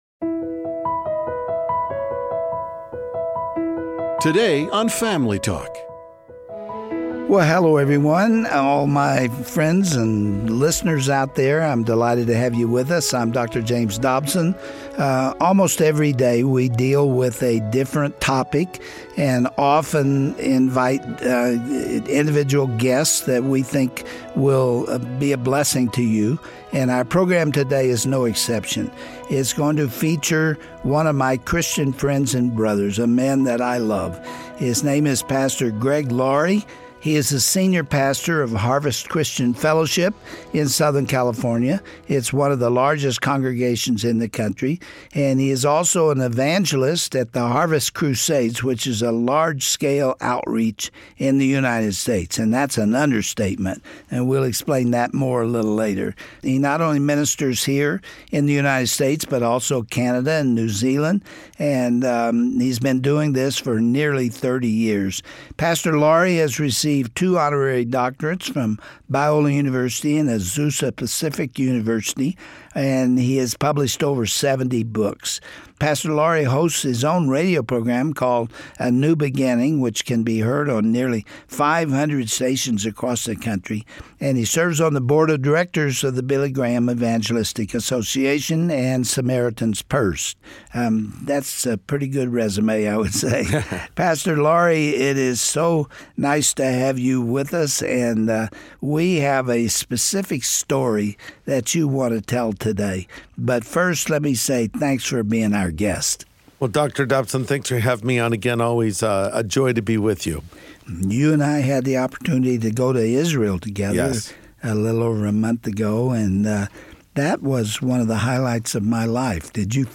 Dr. Dobson welcomes back to Family Talk Pastor Greg Laurie from Harvest Christian Fellowship. Greg shares why he created a film and wrote a book on the faith journey of actor Steve McQueen, and explains his passion for evangelism through his 'Harvest America' events.